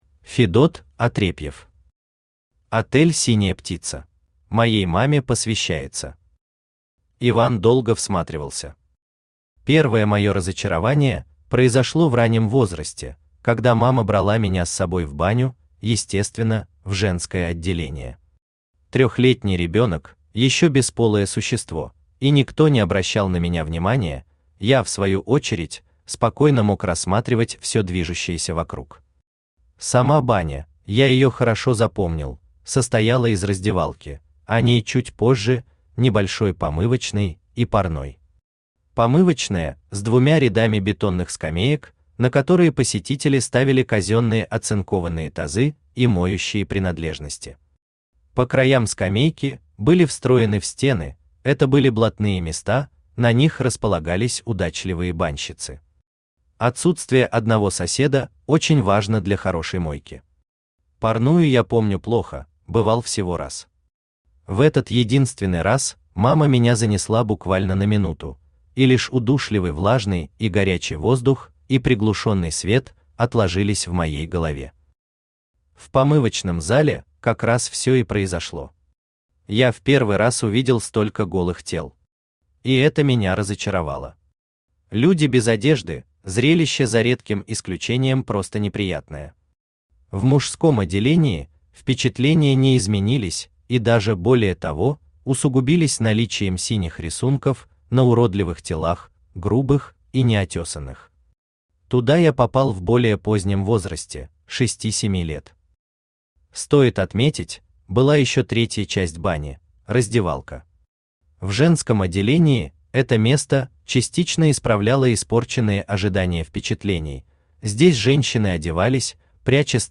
Аудиокнига Отель «Синяя Птица» | Библиотека аудиокниг
Aудиокнига Отель «Синяя Птица» Автор Федот Атрепьев Читает аудиокнигу Авточтец ЛитРес.